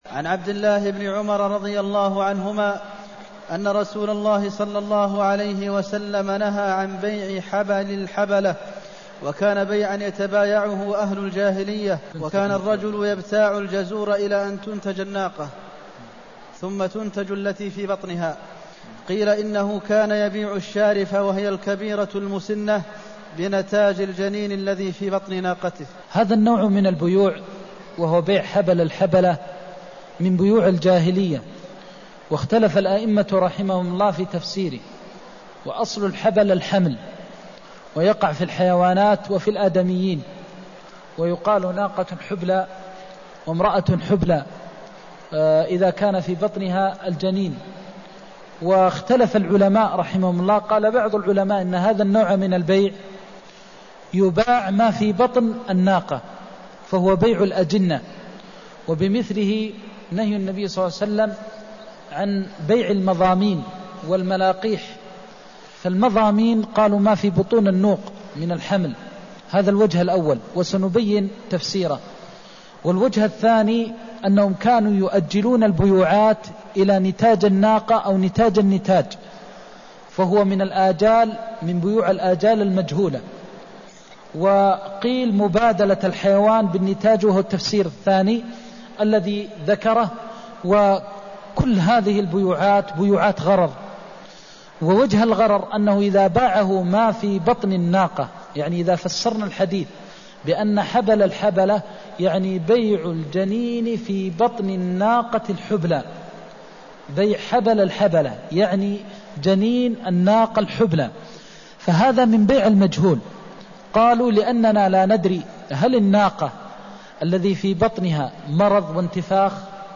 المكان: المسجد النبوي الشيخ: فضيلة الشيخ د. محمد بن محمد المختار فضيلة الشيخ د. محمد بن محمد المختار نهيه عن بيع حبل الحبلة (246)